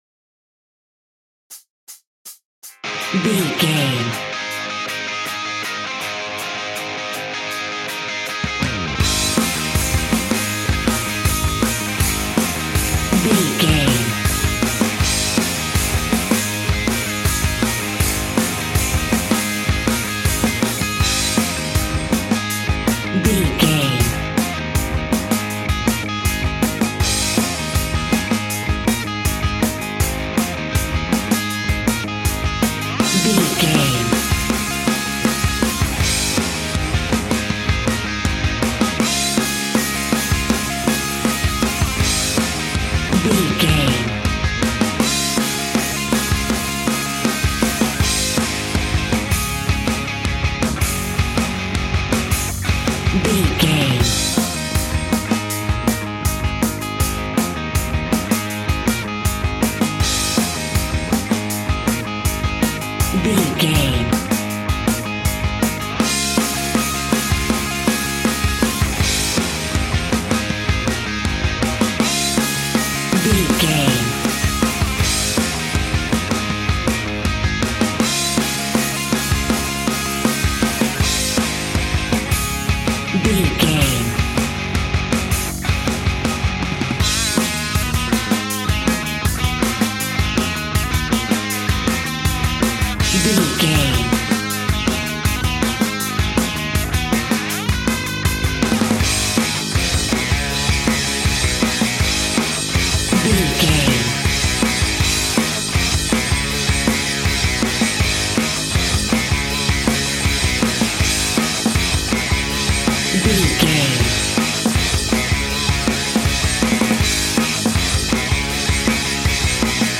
Epic / Action
Fast paced
Ionian/Major
A♭
hard rock
blues rock
distortion
instrumentals
rock guitars
Rock Bass
Rock Drums
heavy drums
distorted guitars
hammond organ